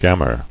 [gam·mer]